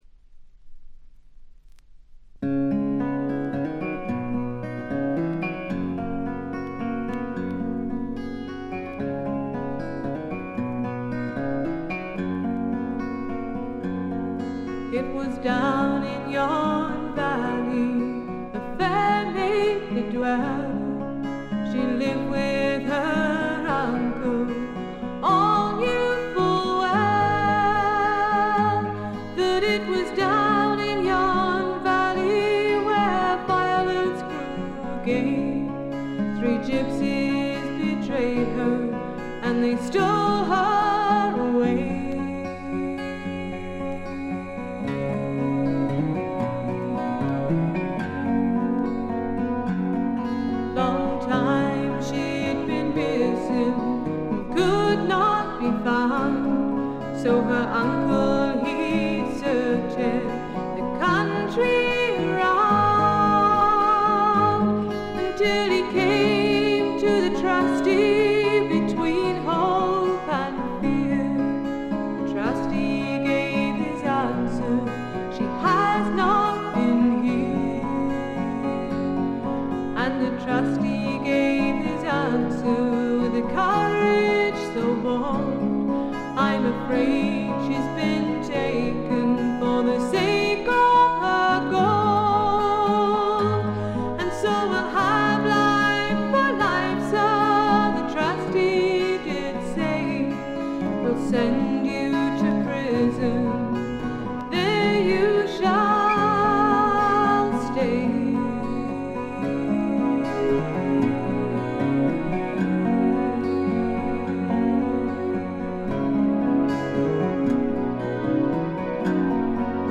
ごくわずかなノイズ感のみ。
基本はギター弾き語りで曲によってベースやシンセが入るといったシンプルな構成で、ゆったりと落ち着いた内容です。
試聴曲は現品からの取り込み音源です。
Bass, Synth
Vocals, Guitar